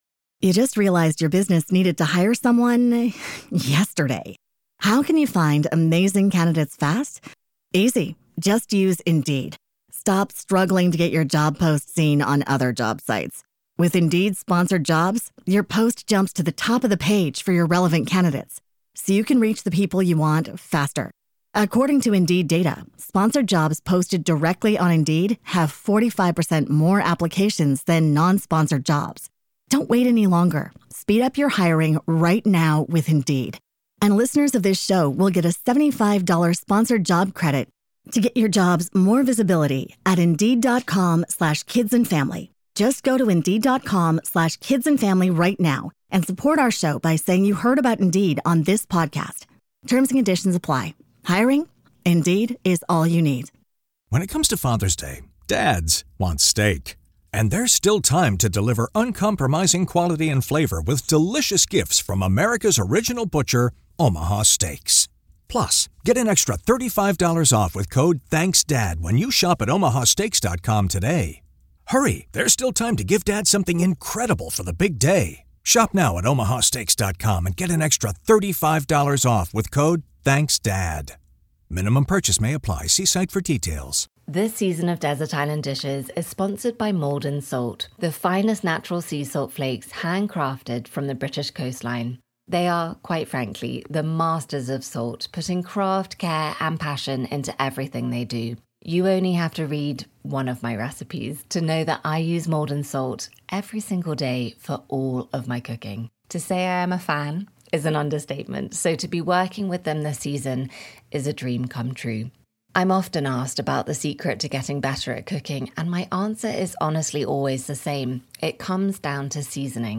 My guest today is Clare Smyth (MBE).